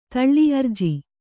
♪ taḷḷi arji